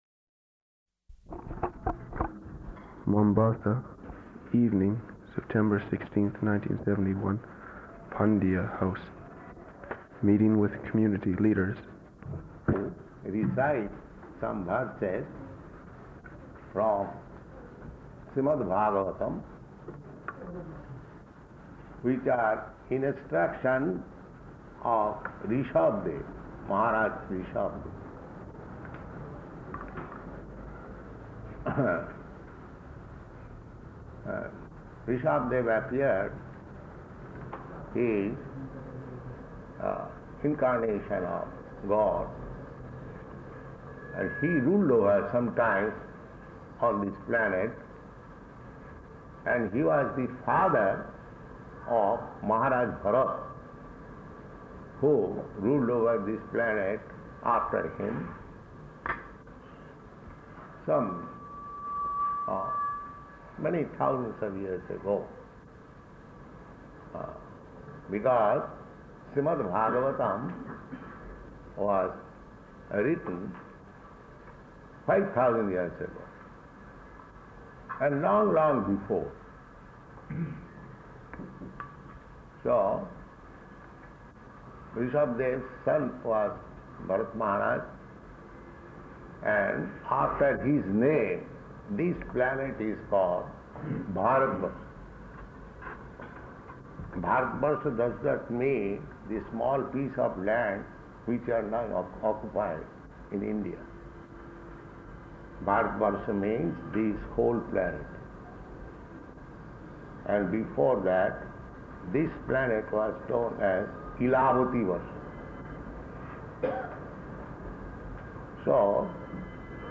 Evening Lecture at Pandiya House Meeting with Community Leaders
Type: Lectures and Addresses
Location: Mombasa